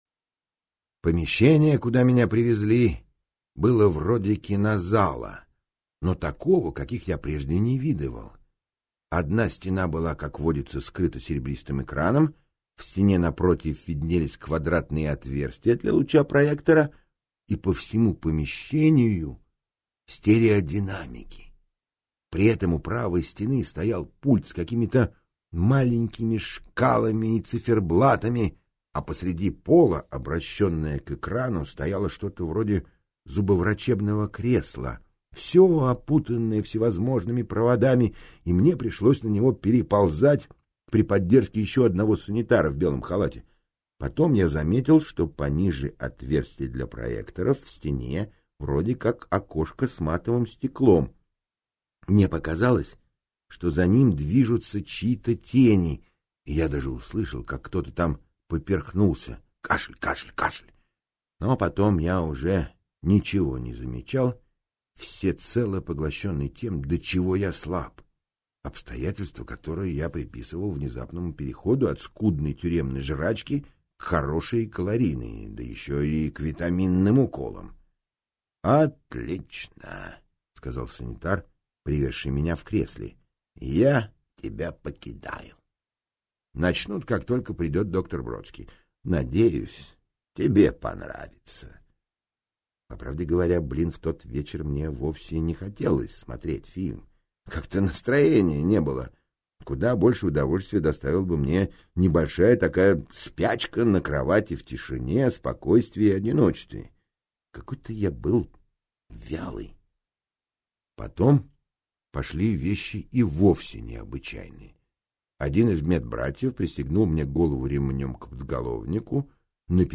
Аудиокнига Заводной апельсин - купить, скачать и слушать онлайн | КнигоПоиск